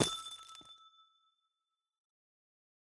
Minecraft Version Minecraft Version latest Latest Release | Latest Snapshot latest / assets / minecraft / sounds / block / amethyst / step9.ogg Compare With Compare With Latest Release | Latest Snapshot
step9.ogg